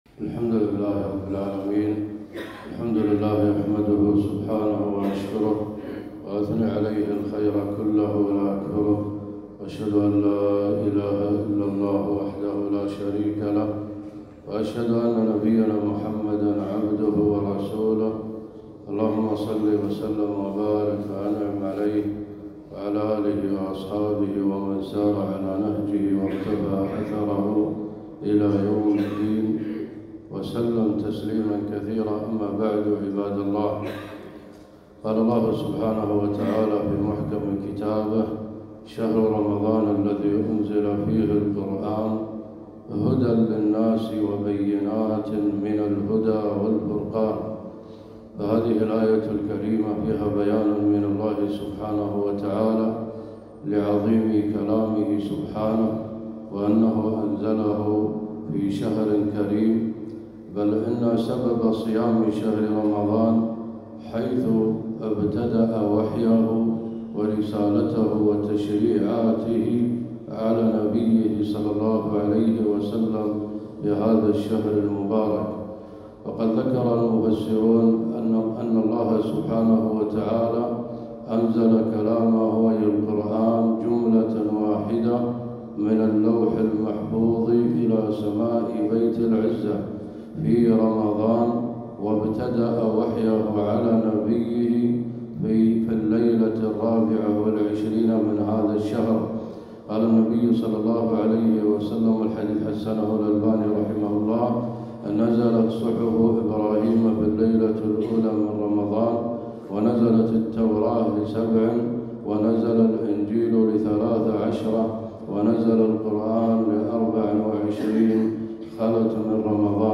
خطبة - شهر رمضان الذي أنزل فيه القرآن - دروس الكويت